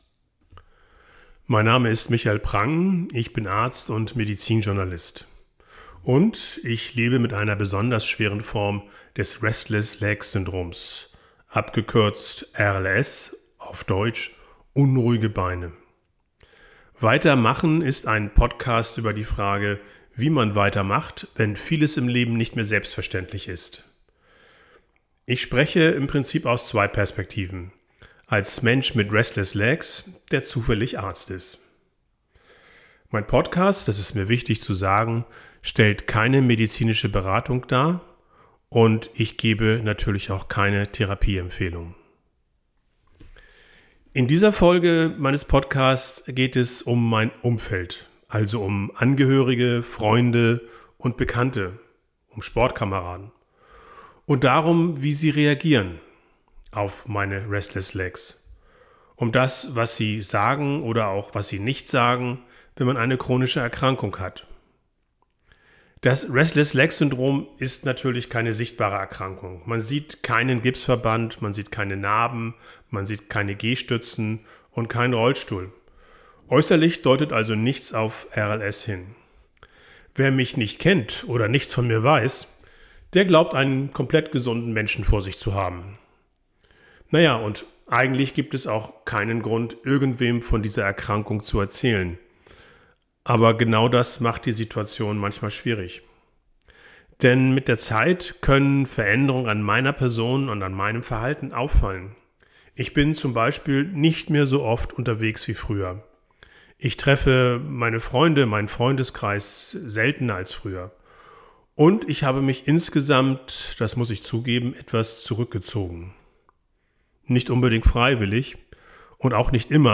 Ich spreche als Arzt und Betroffener über Alltag, Belastung und